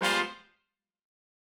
GS_HornStab-G7b2sus4.wav